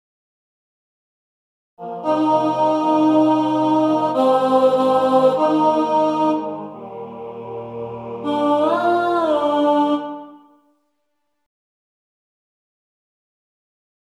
Key written in: E♭ Major
Type: Other male